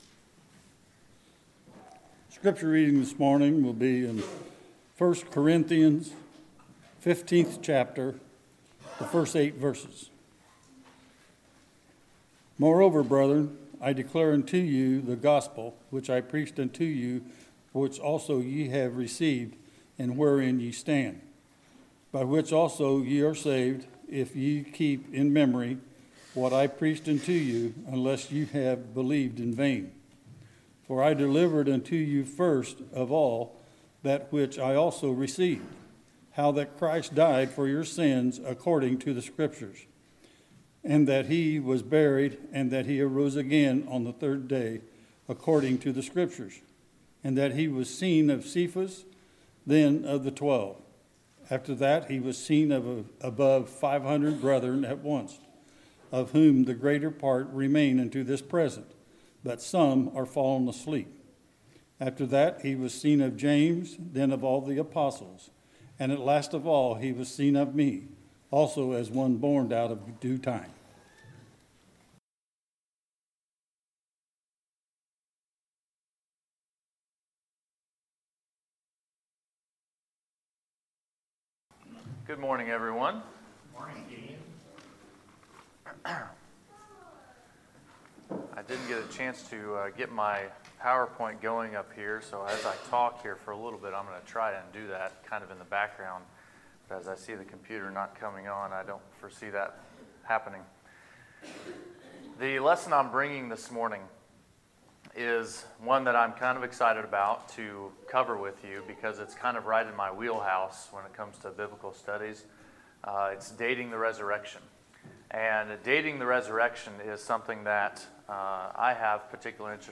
Sermons, May 19, 2019